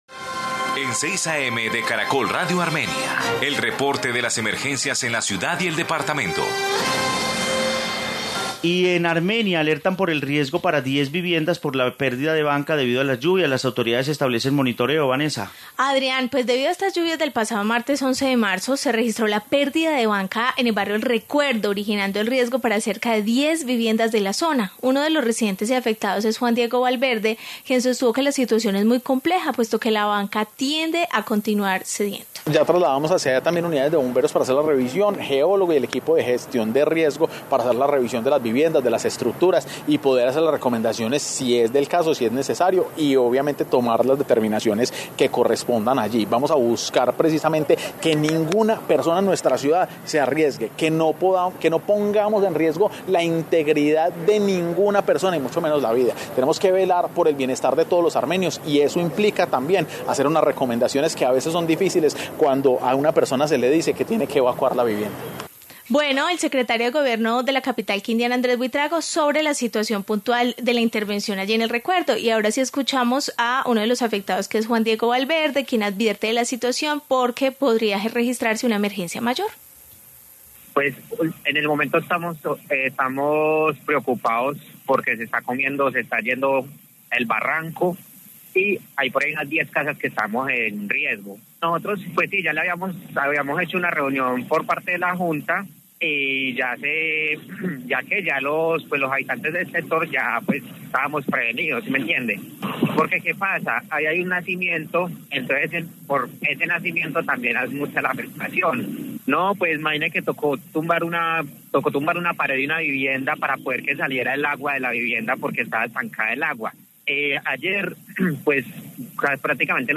Informe emergencia en el Recuerdo de Armenia